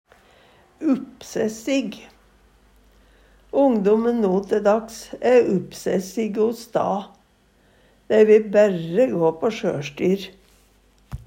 uppsessig - Numedalsmål (en-US)